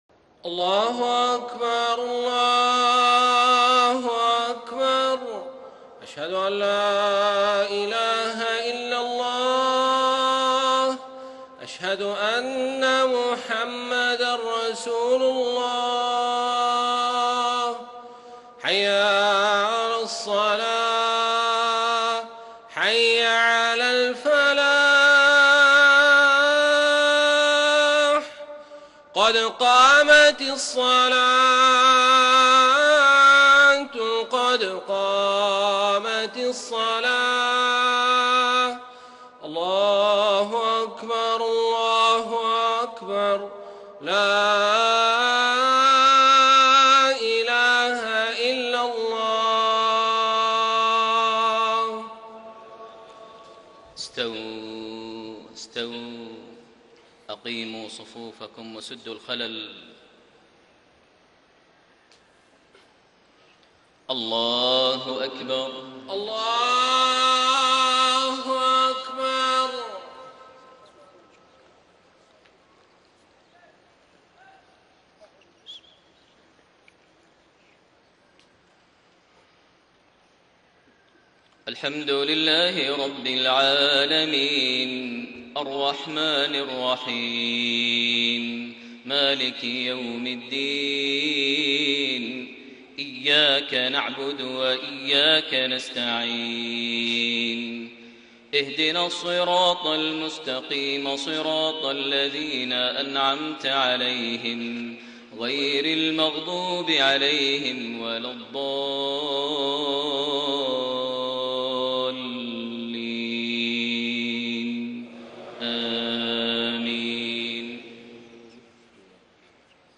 Maghrib prayer from Surah Al-A'laa and Al-Ghaashiya > 1433 H > Prayers - Maher Almuaiqly Recitations